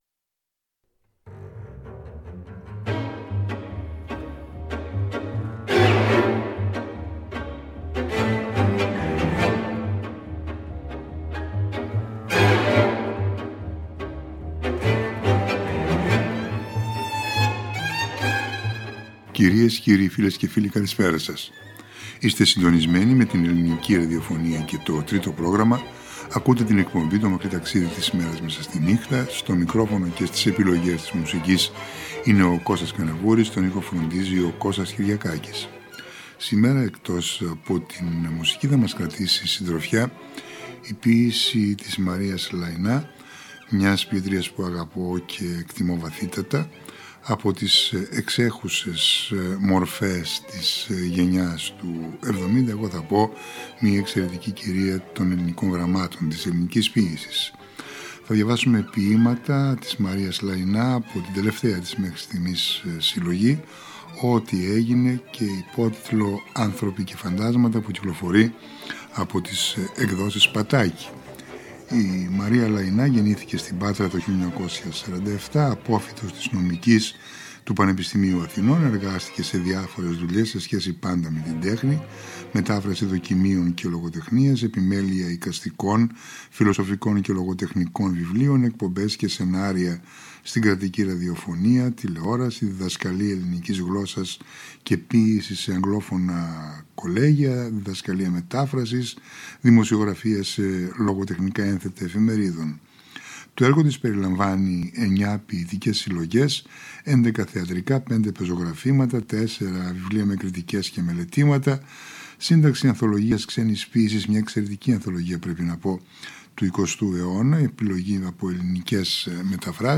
Πάντοτε με μουσικές εξαίσιες.